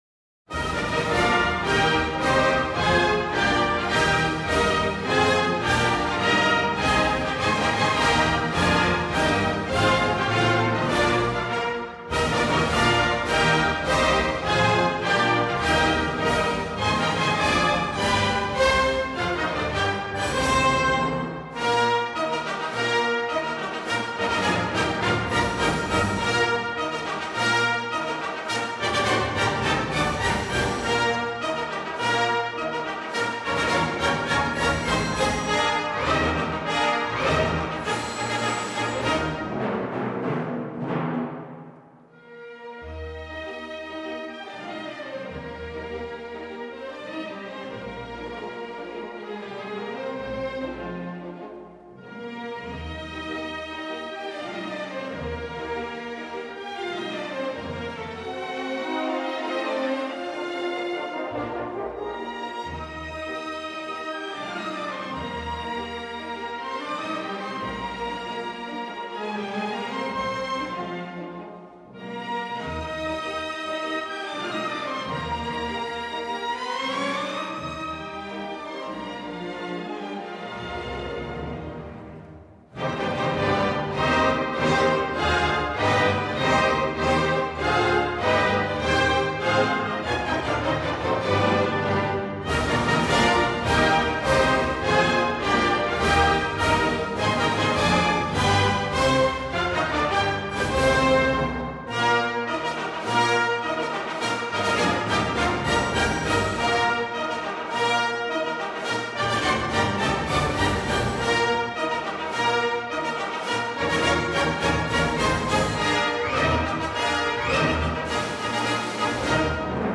마이어베어_[예언자] 제 4막중 대관식 행진곡.mp3